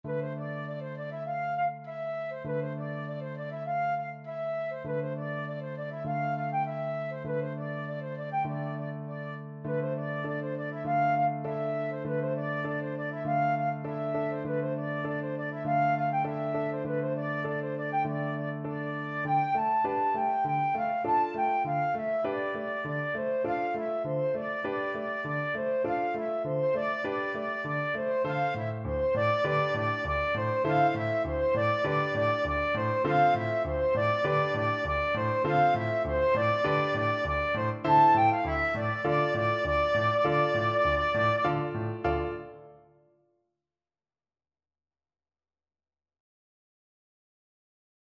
Traditional Music of unknown author.
2/4 (View more 2/4 Music)
World (View more World Flute Music)